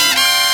TRUMPETS.wav